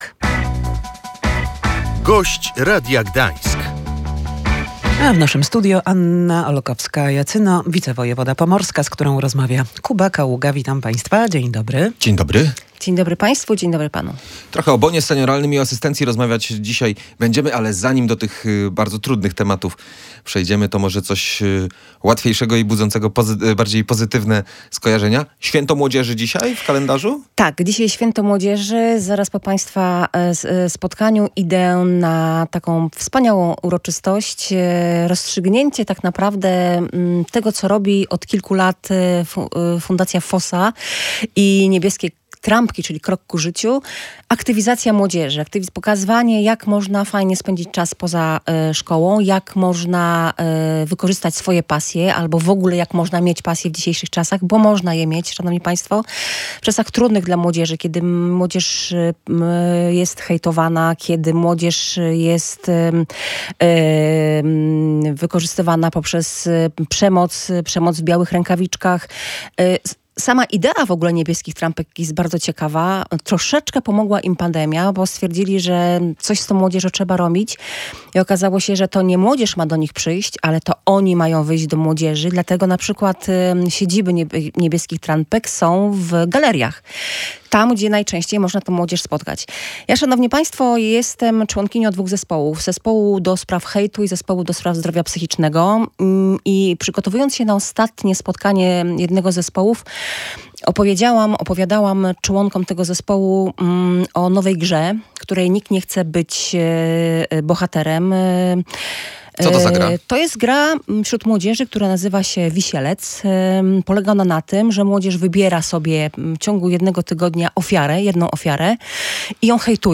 Jest szansa, że w przyszłym roku zacznie obowiązywać ustawa o asystencji osobistej – mówiła w Radiu Gdańsk wicewojewoda pomorska Anna Olkowska-Jacyno. Będzie to wsparcie systemowe dla osób z niepełnosprawnościami i ich rodzin.